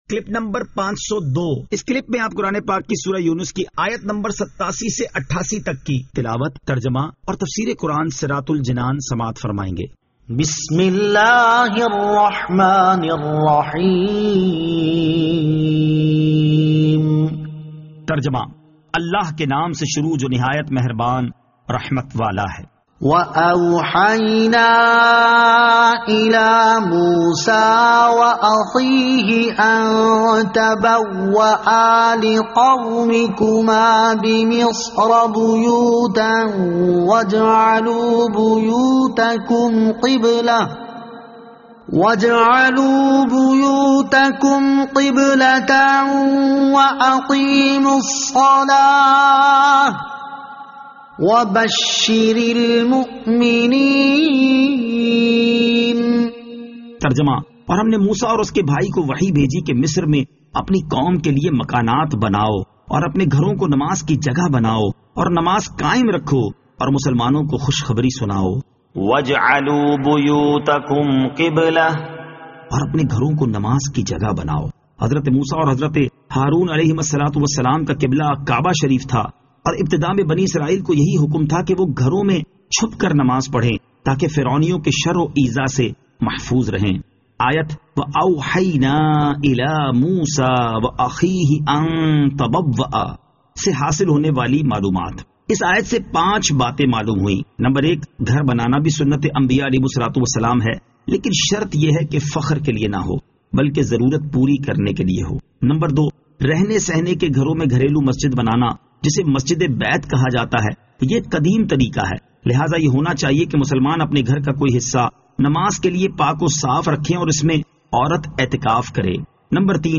Surah Yunus Ayat 87 To 88 Tilawat , Tarjama , Tafseer